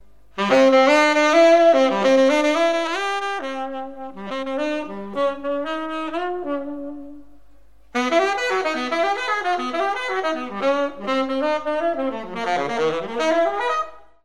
saxofon3.mp3